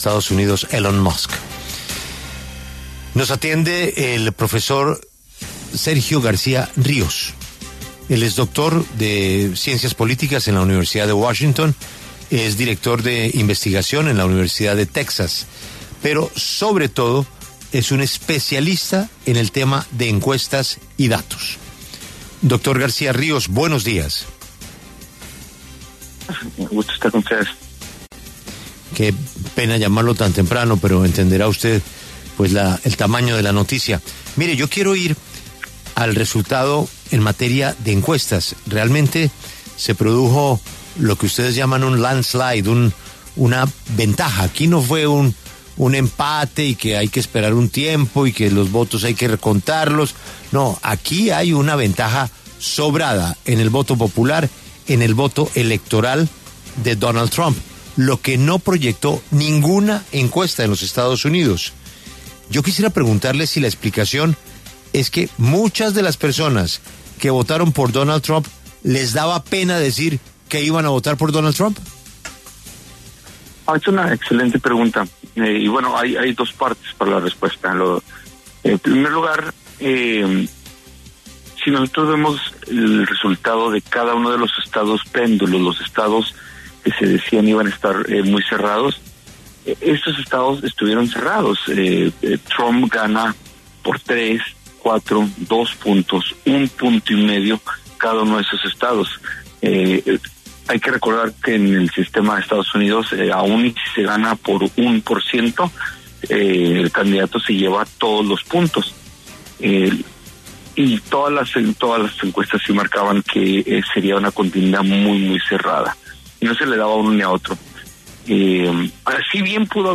conversó con La W